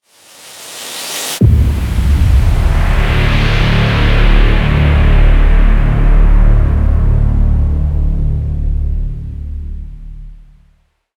woosh.mp3